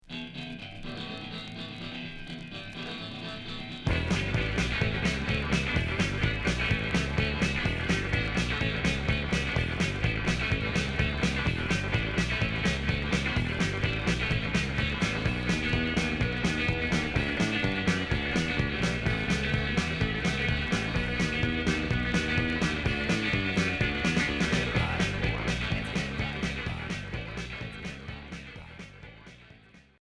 Psychobilly Unique 45t retour à l'accueil